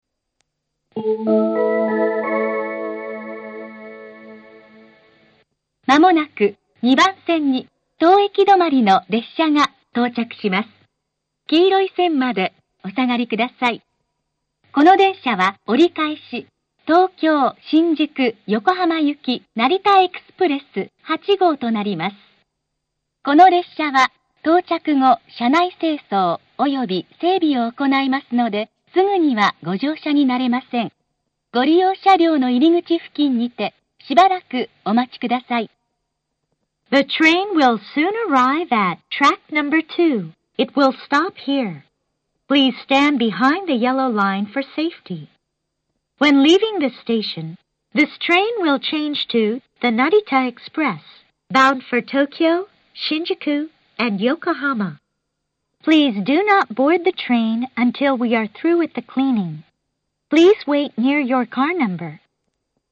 ２番線接近放送 折り返し成田エクスプレス８号東京・新宿・横浜行の放送です。
２番線発車メロディー 発車放送は快速横須賀行です。
narita-airport-2bannsenn-sekkinn.mp3